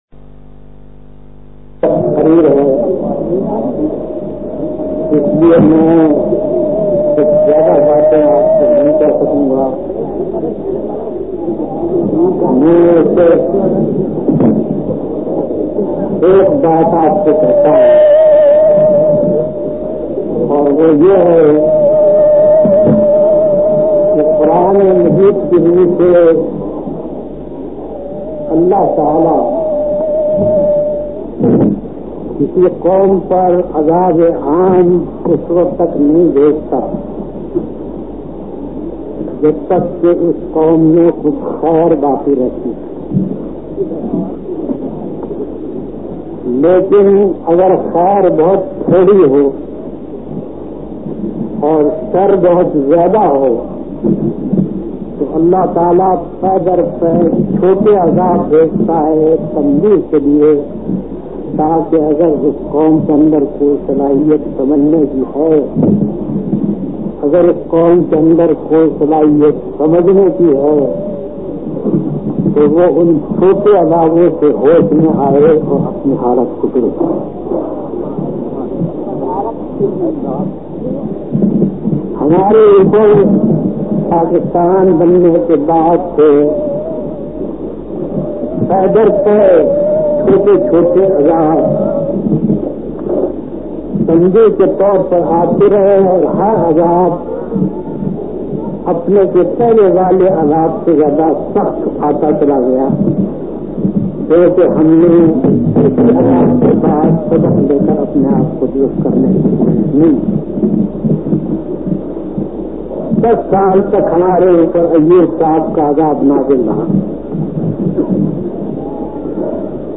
Lectures - Listen/Download